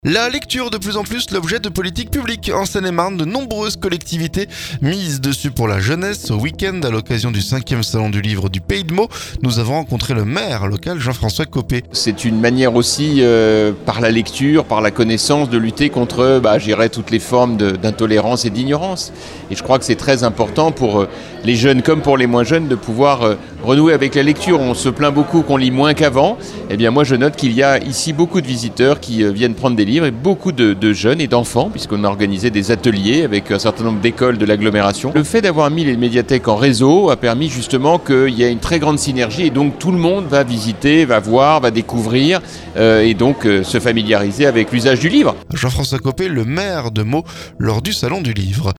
Ce week-end, à l'occasion du 5ème salon du livre du Pays de Meaux, nous avons rencontré le maire Jean-François Copé.